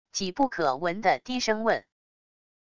几不可闻的低声问wav音频